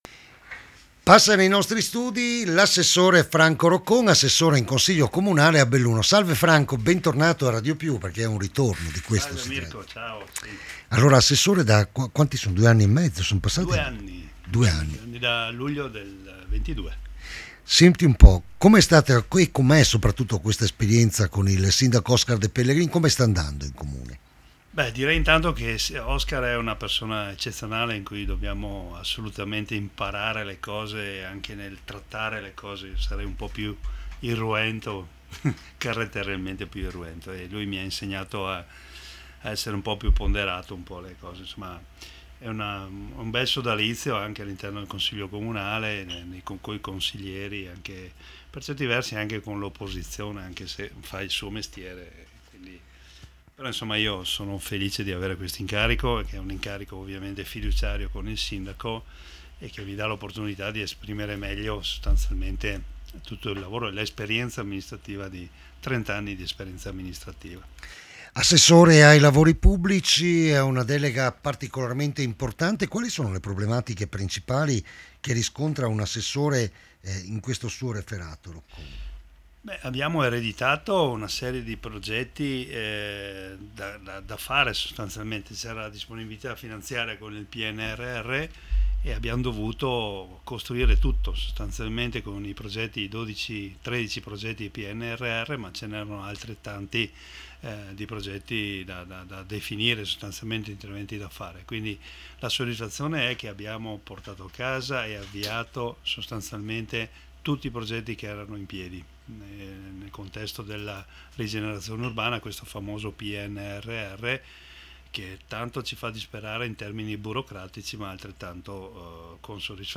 franco-roccon-intervista-2024.mp3